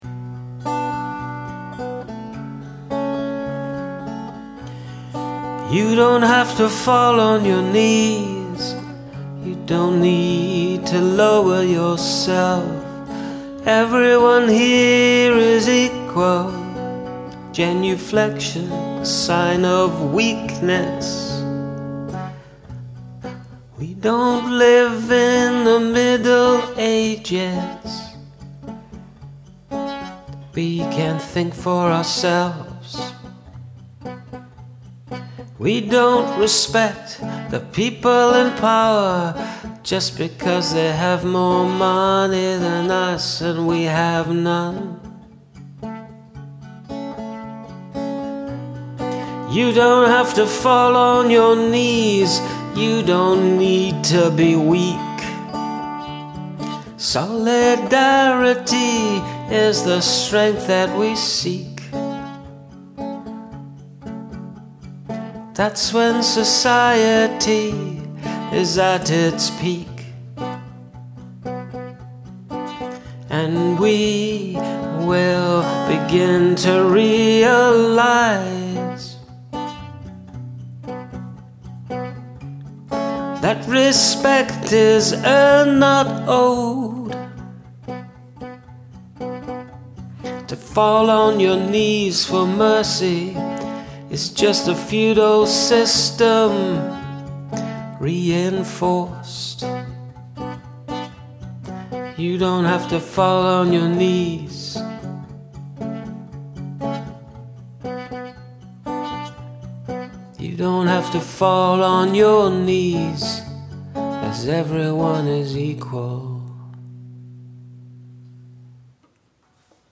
Love the plucking in the second stanza.
Lovely guitar-work as well.
Love the sparse guitar and great melody.